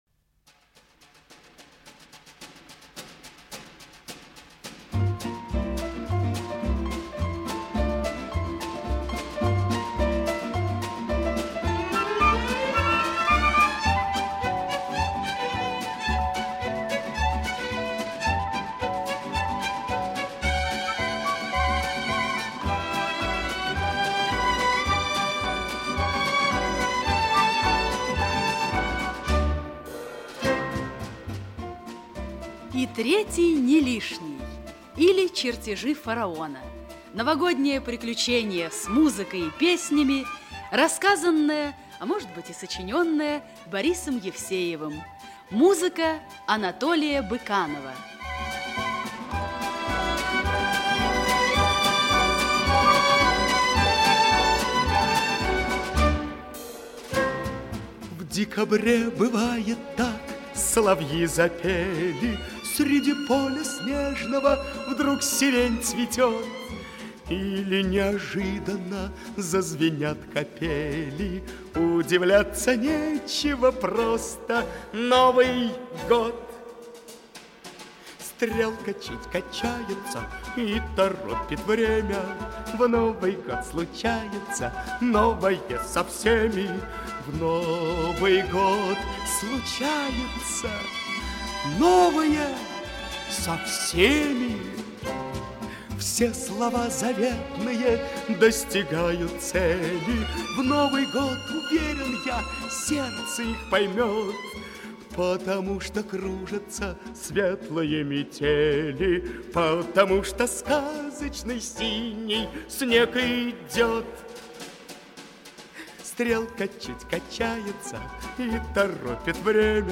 Аудиокнига И третий – не лишний, или Чертежи Фараона | Библиотека аудиокниг
Aудиокнига И третий – не лишний, или Чертежи Фараона Автор Борис Евсеев Читает аудиокнигу Актерский коллектив.